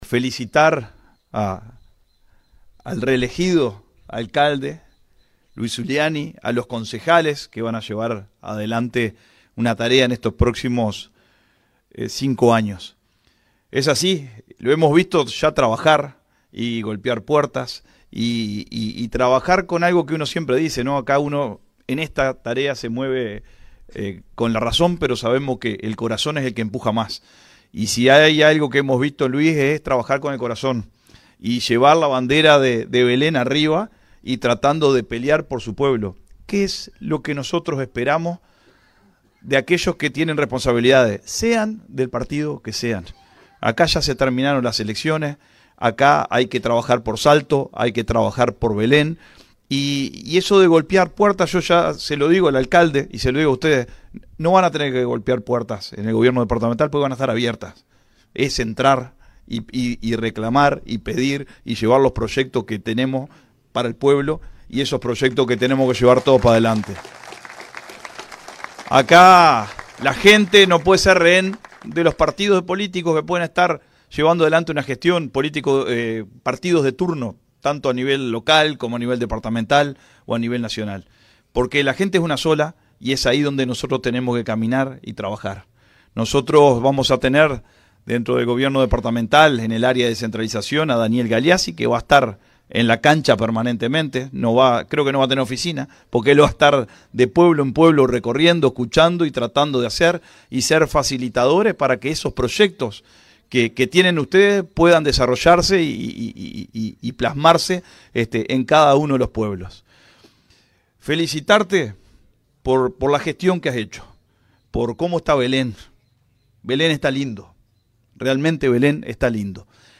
La ceremonia se desarrolló ante un importante marco de público y contó con la presencia de autoridades locales, departamentales y nacionales.
El intendente departamental, Dr. Carlos Albisu, fue el encargado de cerrar el acto con un mensaje de fuerte respaldo institucional. Felicitó a Zuliani y al nuevo Concejo, y reafirmó el compromiso del gobierno departamental con la gestión local.